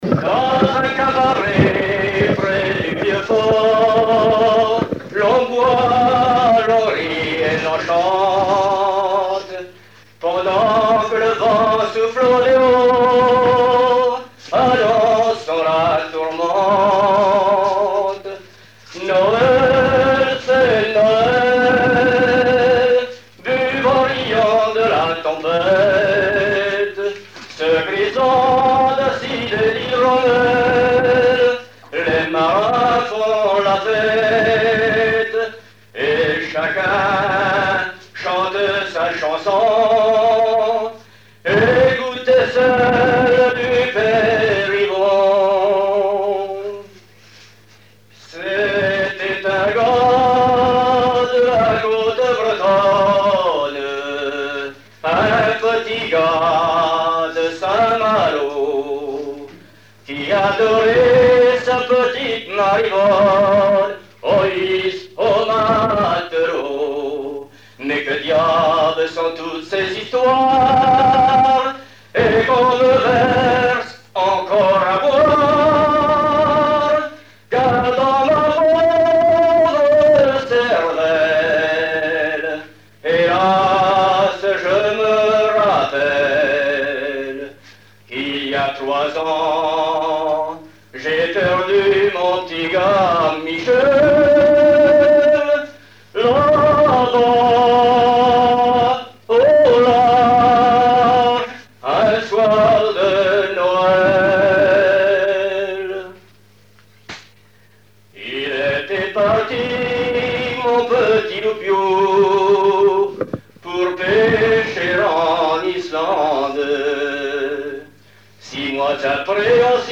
Genre strophique
collectage pour le répertoire du Vir'couët
Pièce musicale inédite